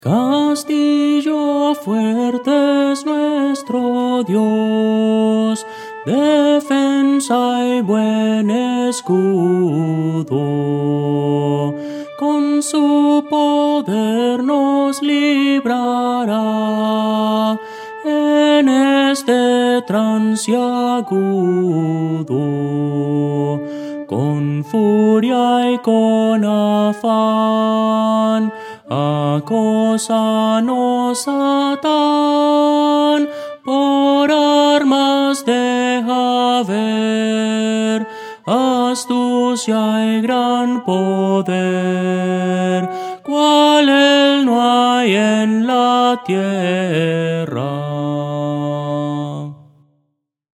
Voces para coro
Soprano – Descargar
Audio: MIDI